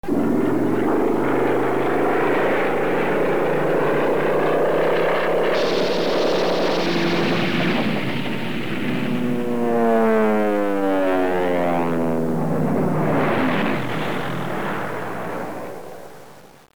Stuka